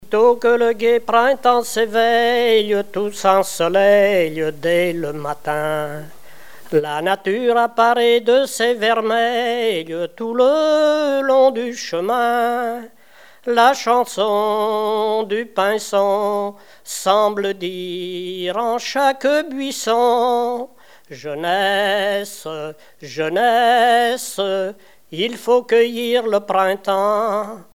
Genre strophique
Répertoire de chansons populaires anciennes
Pièce musicale inédite